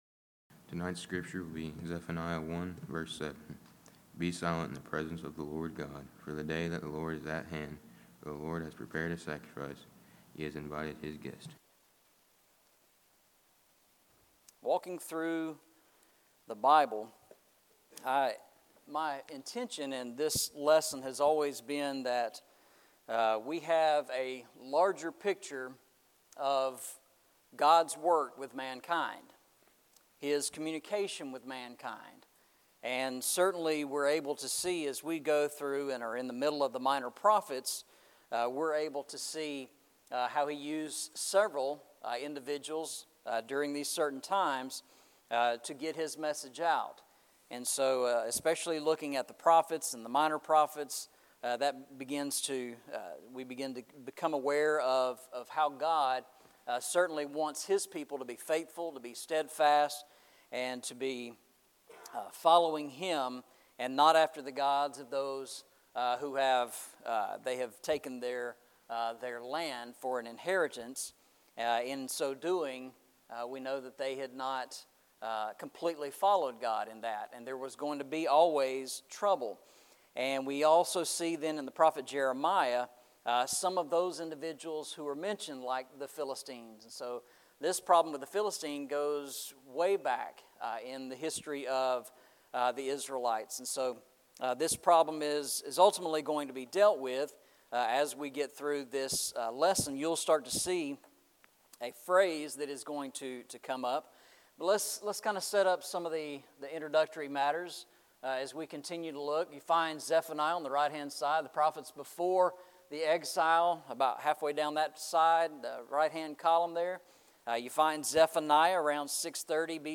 Eastside Sermons Passage: Zephaniah 1:7 Service Type: Sunday Evening « Paradoxes of the Cross Can We Comprehend the Glory of God?